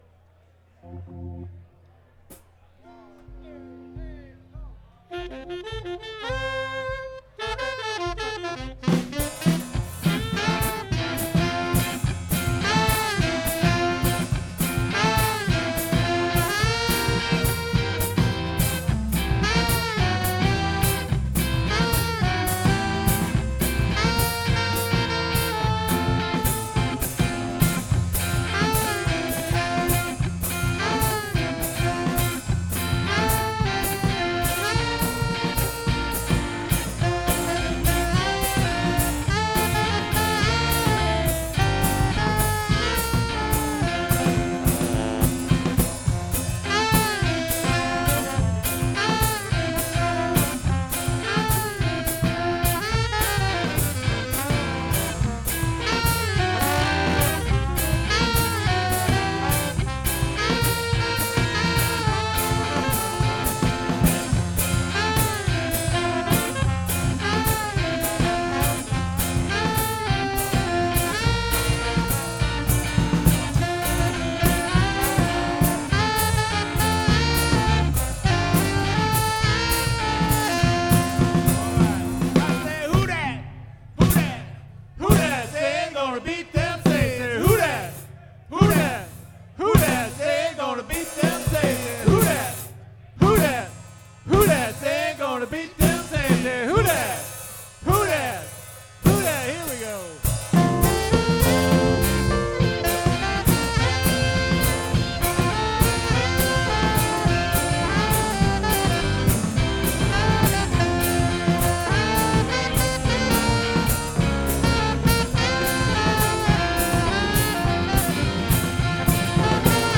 YouTube: Chris Barber Big Band Click to open Audio File: Mardi Gras 2025